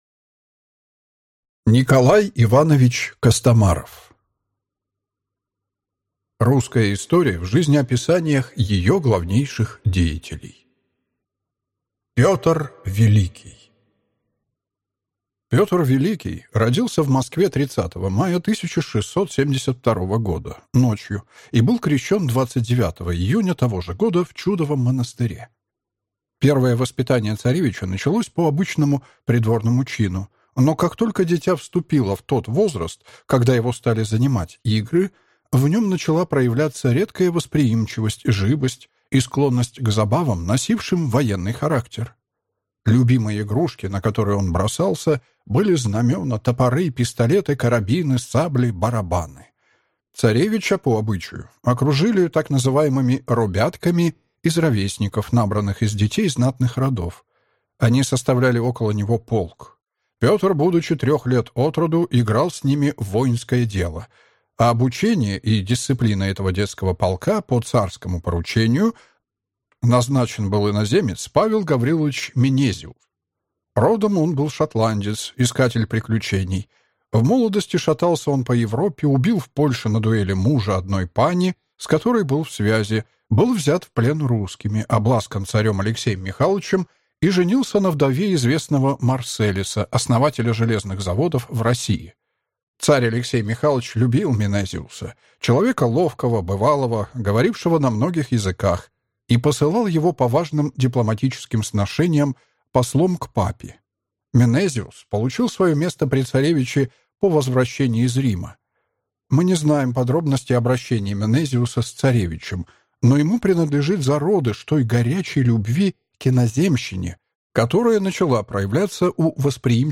Аудиокнига Русская история в жизнеописаниях ее главнейших деятелей. Петр Великий | Библиотека аудиокниг